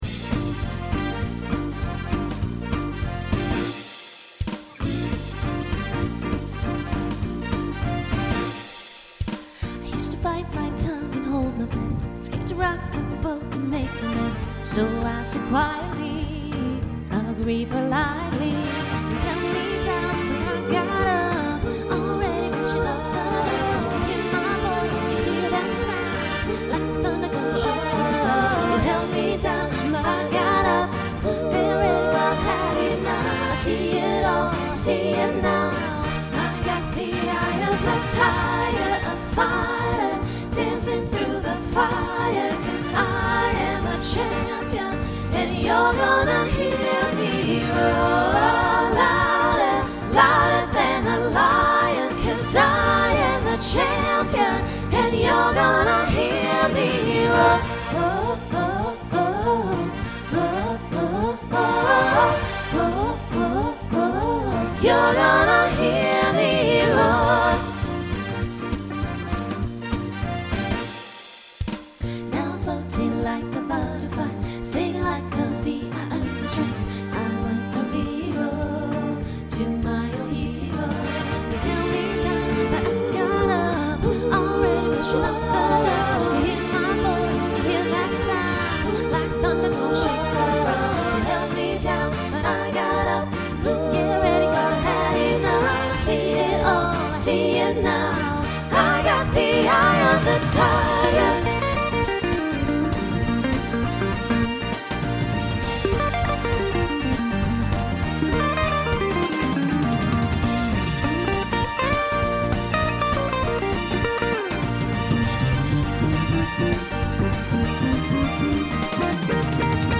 Voicing SATB Instrumental combo Genre Pop/Dance , Swing/Jazz
Mid-tempo